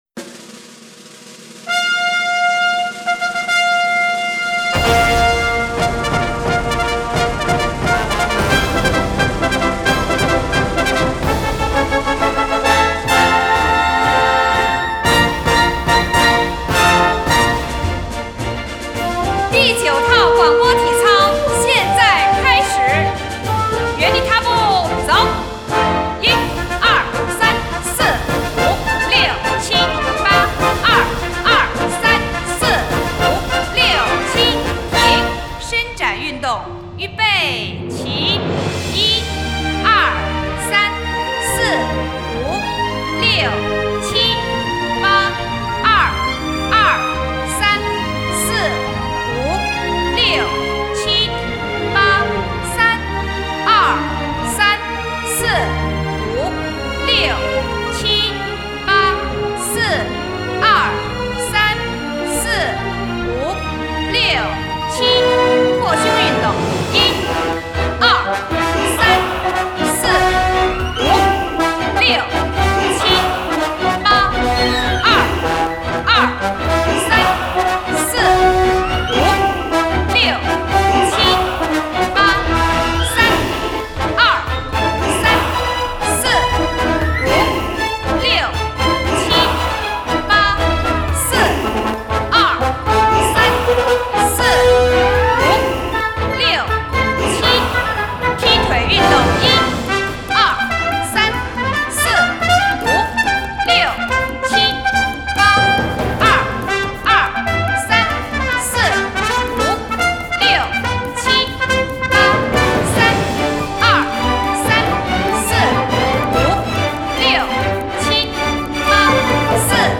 配乐-带口令